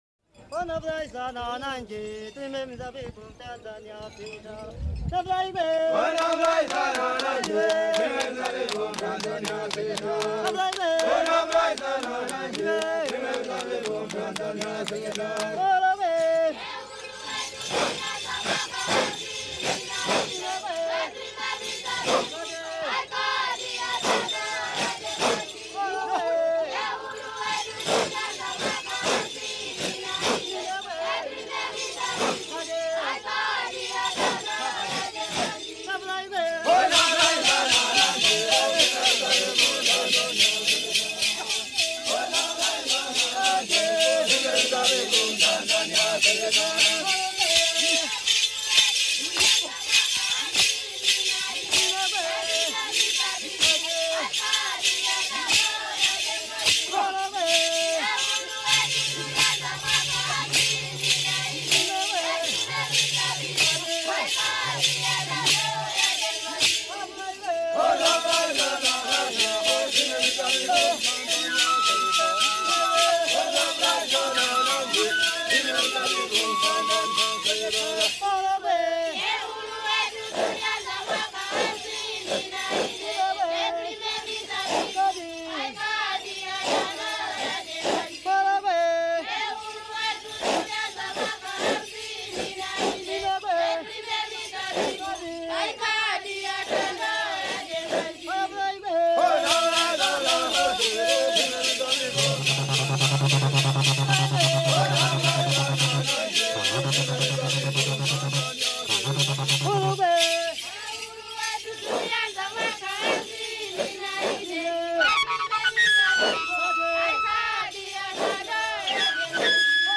CANTI E MUSICHE CERIMONIALI DA UGANDA, KENYA E TANZANIA